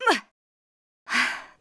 fishing_fail_v.wav